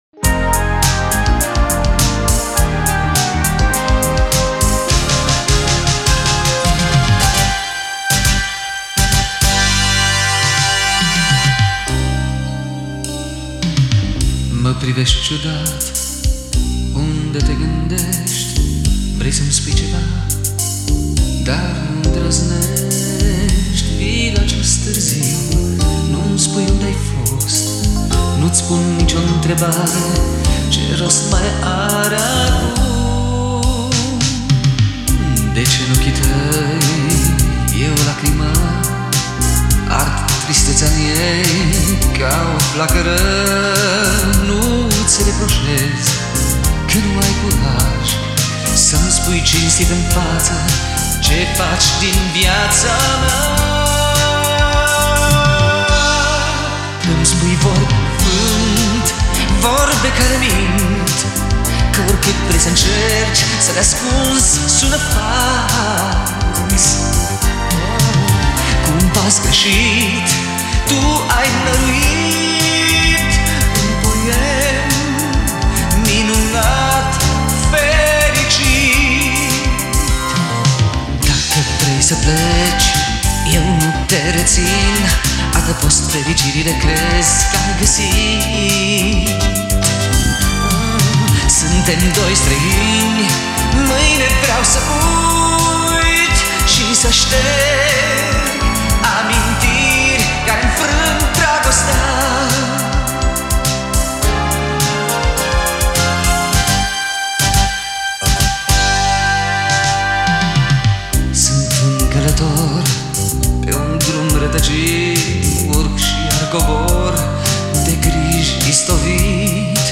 interpretată cu mare sensibilitate